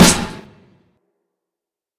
Snares
CLUNK_SNR.wav